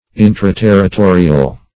Search Result for " intraterritorial" : The Collaborative International Dictionary of English v.0.48: Intraterritorial \In`tra*ter`ri*to"ri*al\, a. Within the territory or a territory.